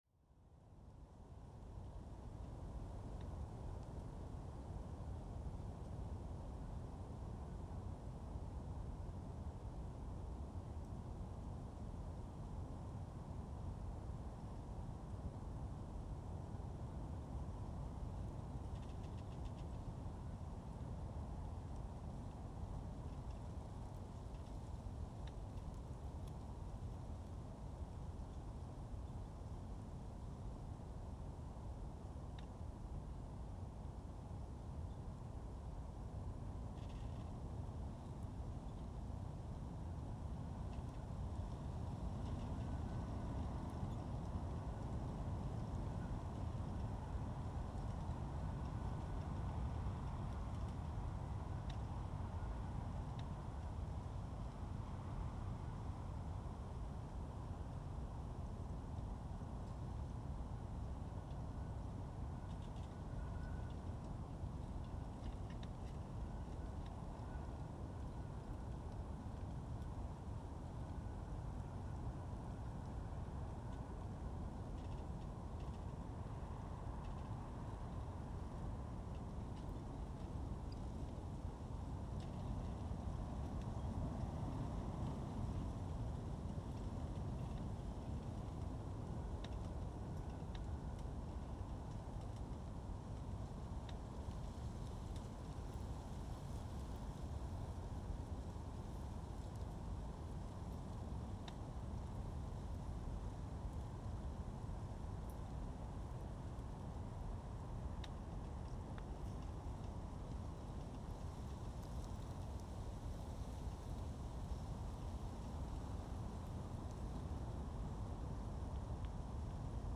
Gust in Stafholtstungur.
It is possible this happened to me in December when I recorded gust over night in the country side, west Iceland. But I am still not sure, because when all the straws on the ground were rubbing each other, they made a sound or a constant noise which was limited to specific frequency range. There is no other recording I have made which sounds as differently between different headphones and speakers as this one. I have not EQ this recording much, just pulled down the subsonic at 20Hz and slightly lifted 250Hz (+/-100Hz) The microphone was about 30-50cm from the ground.
This recording starts very calm, with an „intro“.